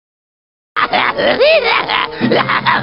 Tazz Laugh-sound-HIingtone
tazz-laugh_21526.mp3